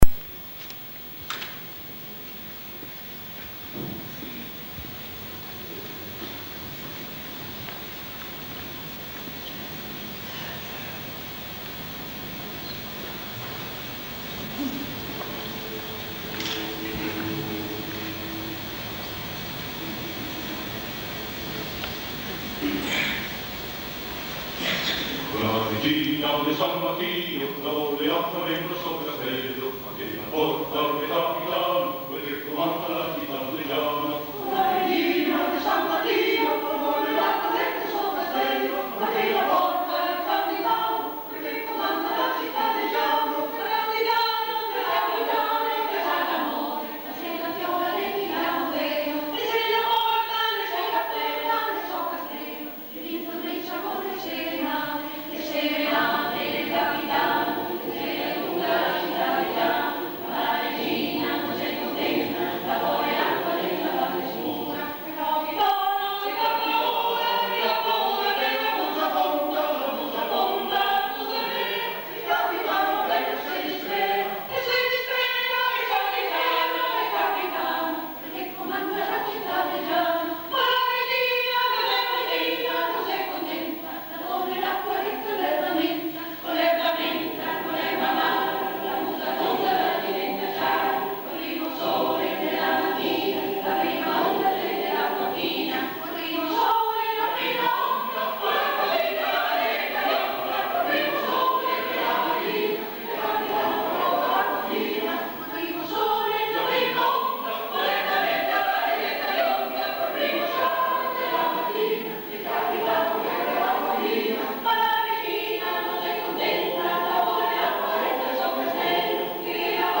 In questa occasione sono state effettuate alcune registrazioni; la qualit� naturalmente � quella amatoriale in sala aperta.